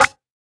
edm-perc-50.wav